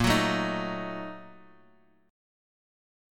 A#M7sus4#5 chord